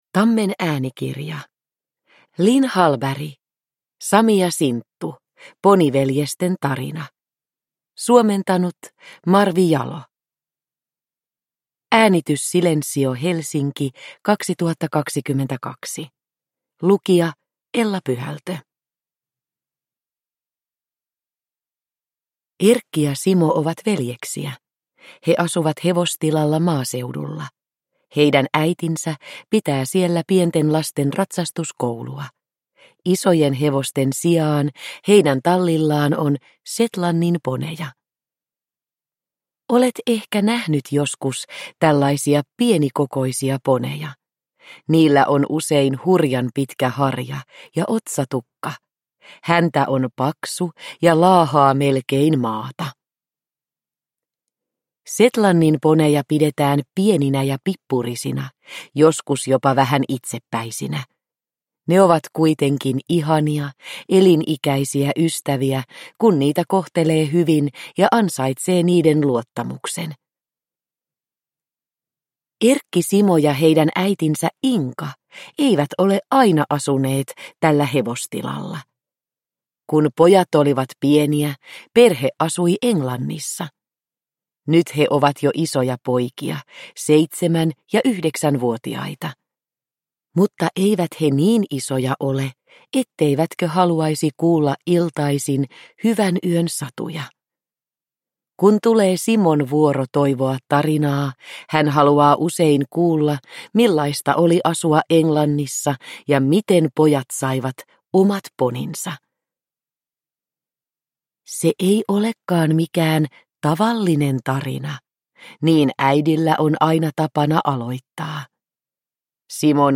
Sami ja Sinttu. Poniveljesten tarina – Ljudbok – Laddas ner